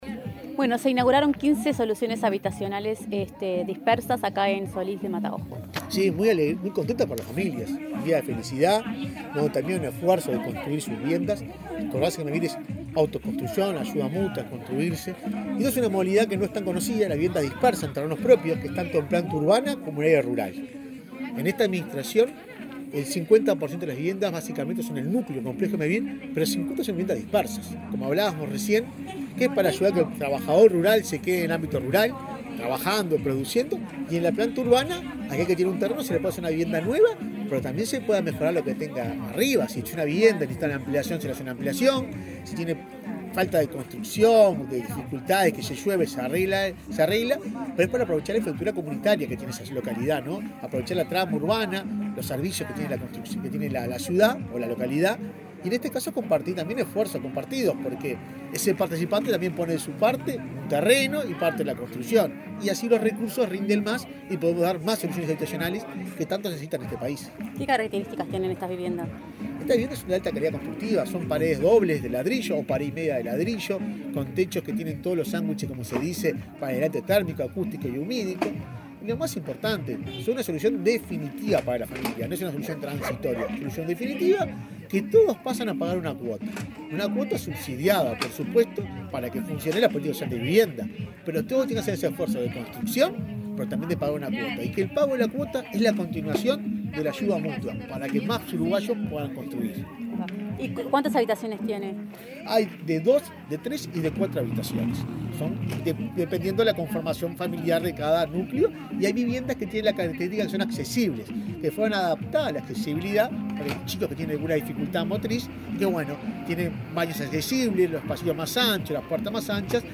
Entrevista al presidente de Mevir, Juan Pablo Delgado
El presidente de Mevir, Juan Pablo Delgado, dialogó con Comunicación Presidencial, luego de inaugurar viviendas dispersas en Solís de Mataojo,